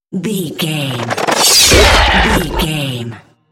Whoosh metal sword creature
Sound Effects
Atonal
whoosh